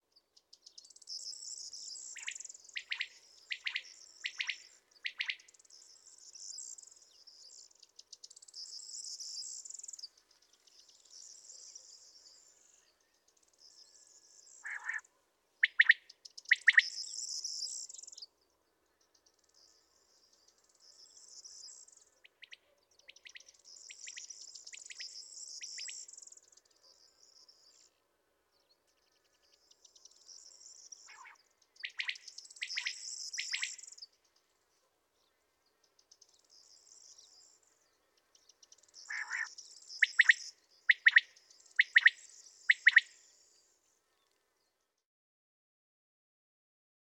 CD2-44: Common Quail Coturnix coturnix Gran Canaria, Canary Islands, Spain, 19 April 2001. In this assembly of singing quails, all deliver their kiss-me-quick song rather rapidly.
2-44-An-assembly-in-the-Canary-Islands.wav